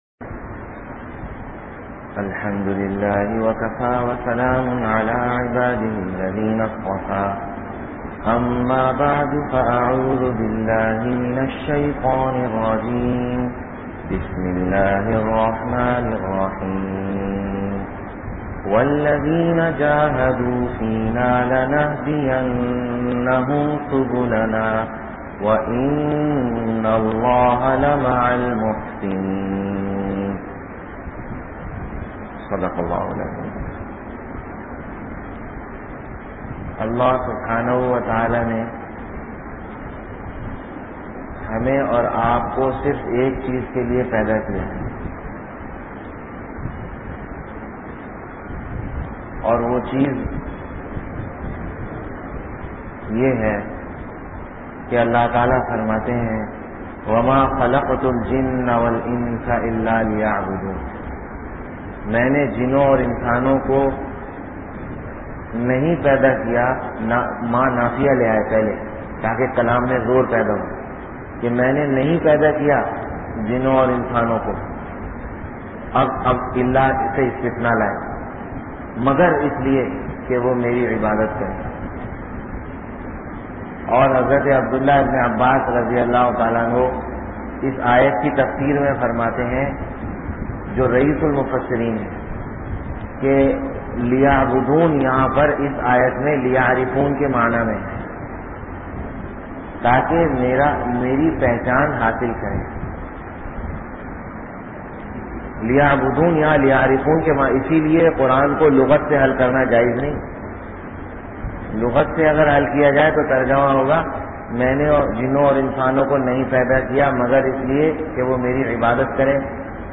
Mujahde Ki Ahmiat (Islahi Bayan Sunday Bayaan)